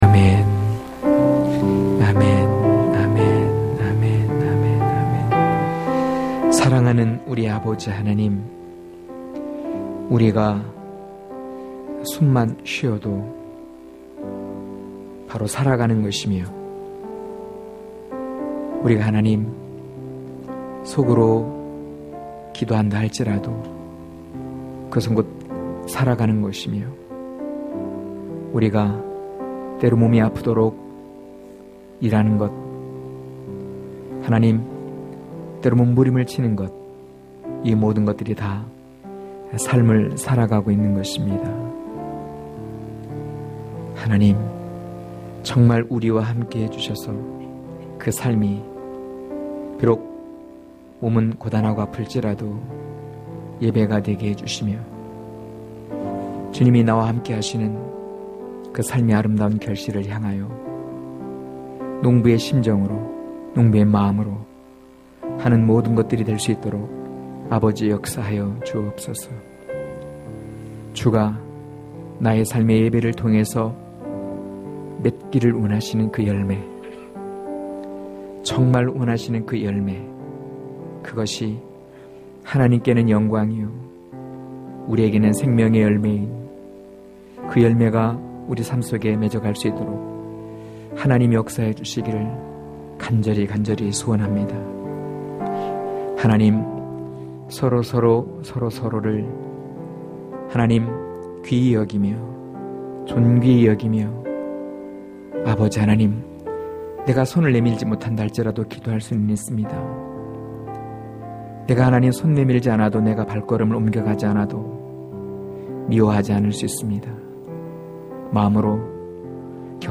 강해설교 - 5.내가 살아가는 이유...(요일2장18-29절)